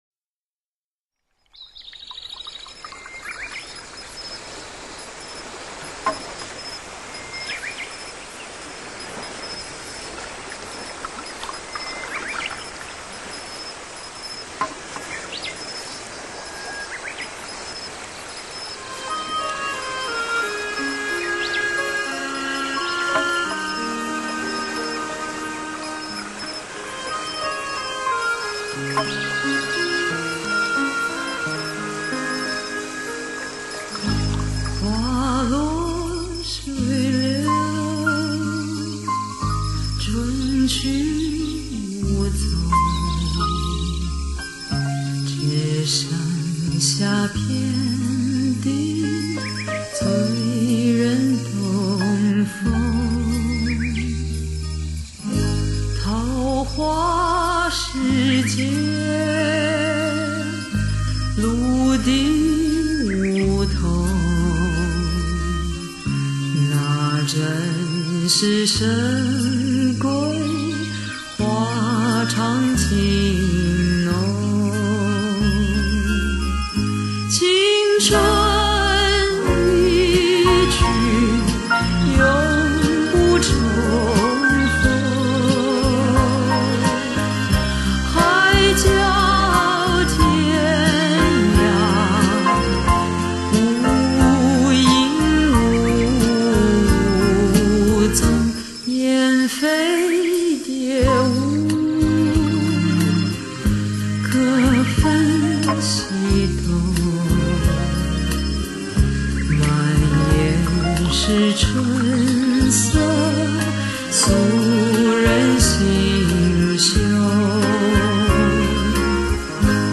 低沉优雅的嗓音，蕴含着成熟女性特有的性感。